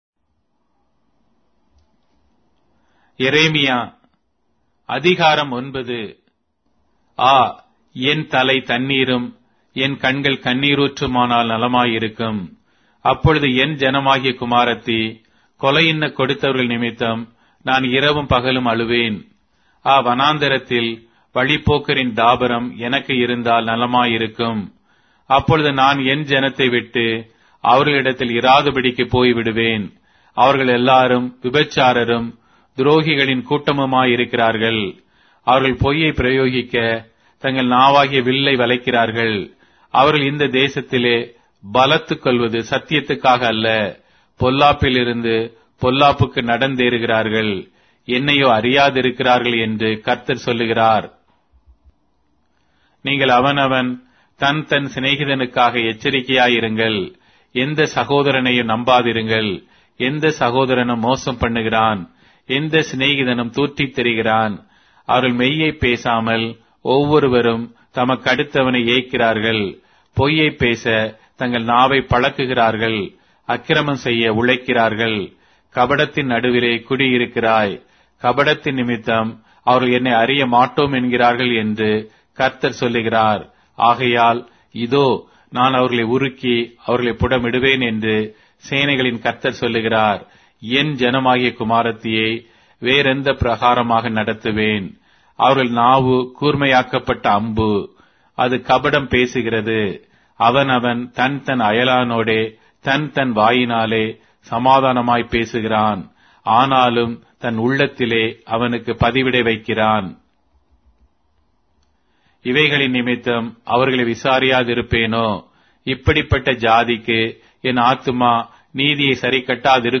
Tamil Audio Bible - Jeremiah 41 in Knv bible version